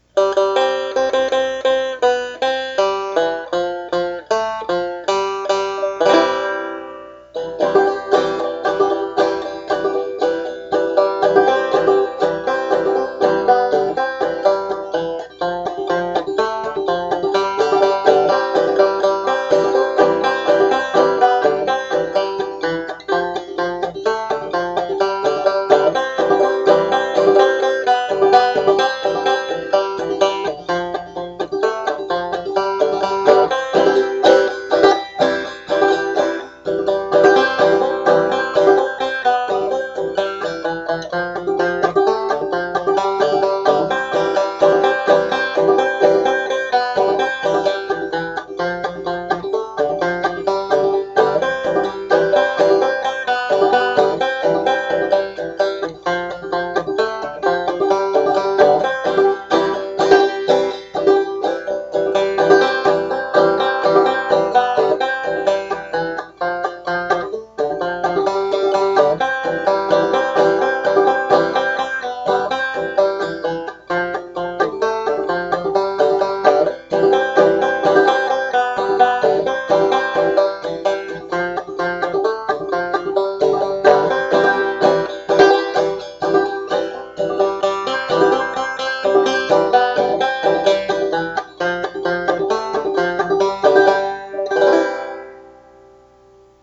banjo
Words and music: English traditional